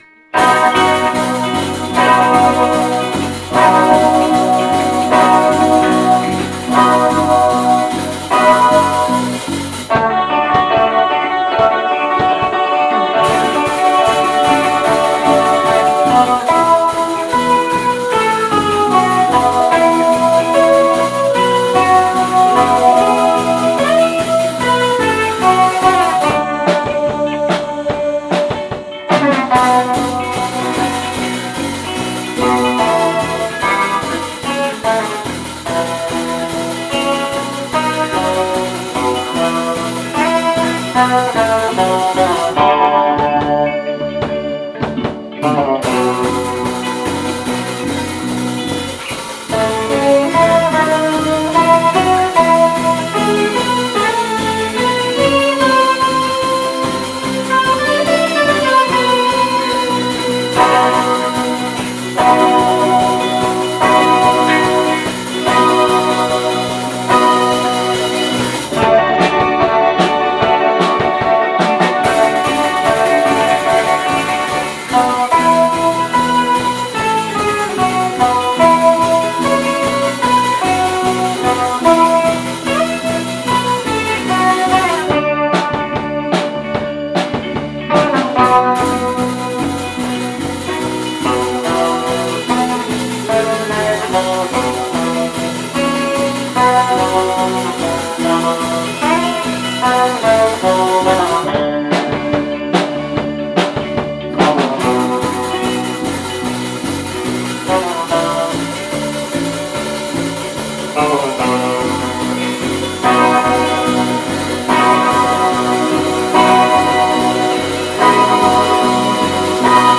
2019 Summer Live